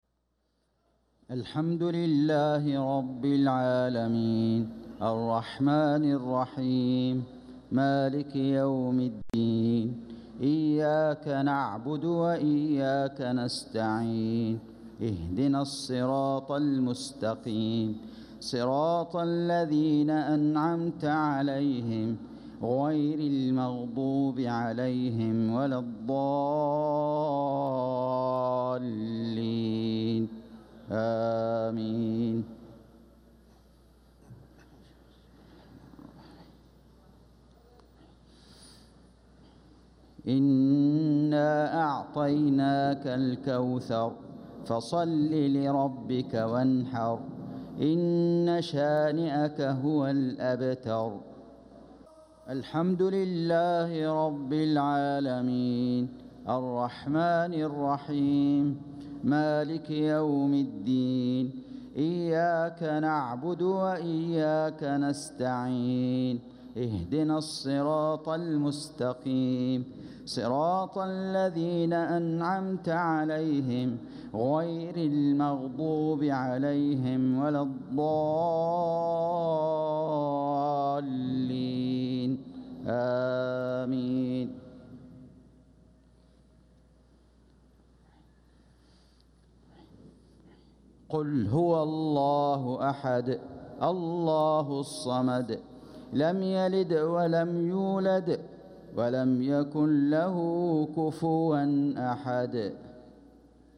صلاة المغرب للقارئ فيصل غزاوي 23 صفر 1446 هـ
تِلَاوَات الْحَرَمَيْن .